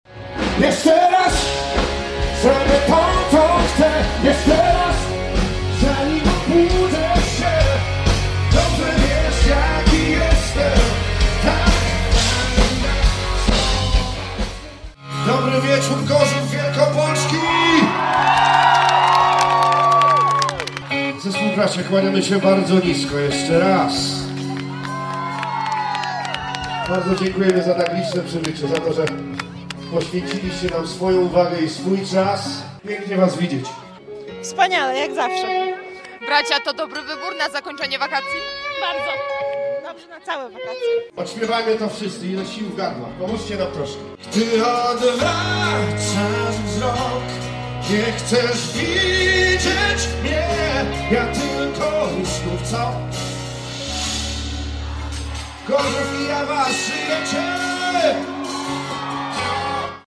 Artyści już od pierwszych chwil zawładnęli publicznością: